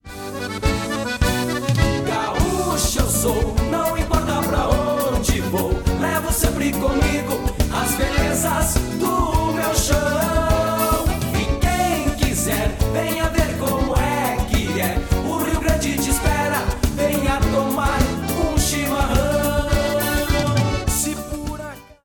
This is a sound sample from a commercial recording.
It is of a lower quality than the original recording.